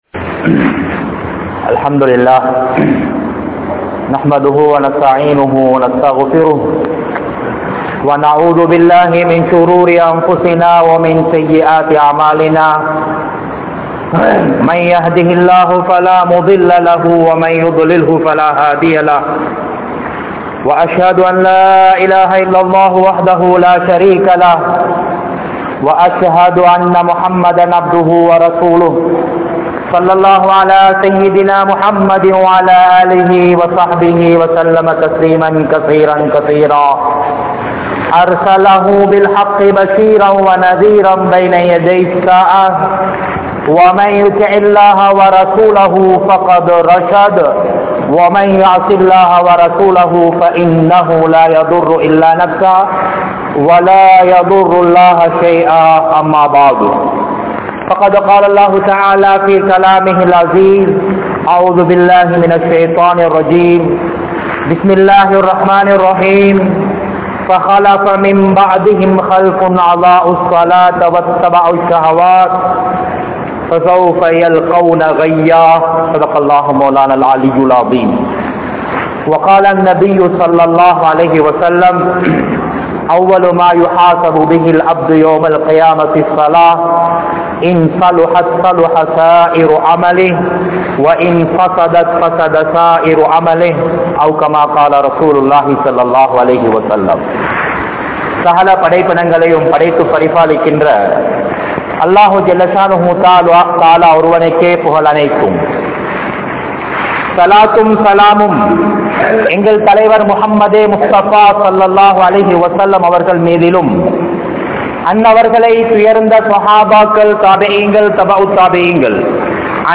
Tholuhaiyai Marantha Manitharhal (தொழுகையை மறந்த மனிதர்கள்) | Audio Bayans | All Ceylon Muslim Youth Community | Addalaichenai